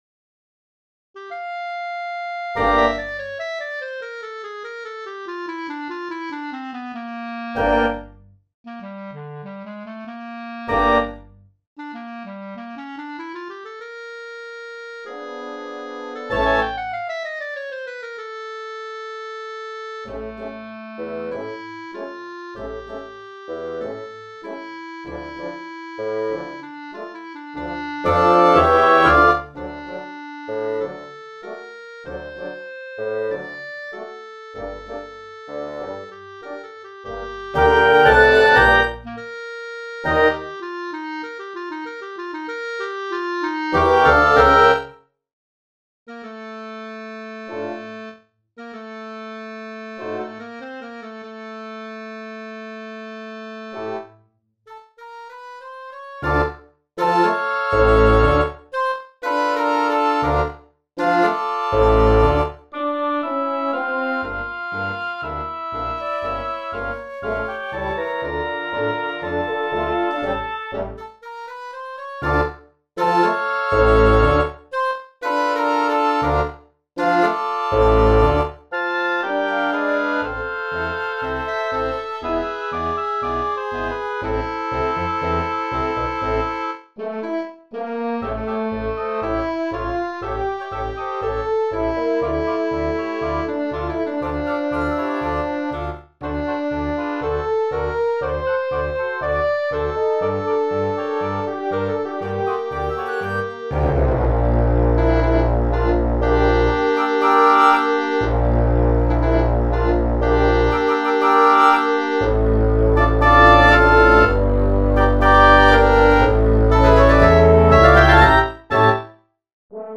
Chamber music: Double wind quintet
tango tzigane
(2 Fl, 2 Ob, 2 Cl, 2 Bsn, 2 Hn, Cbs) (6 min.).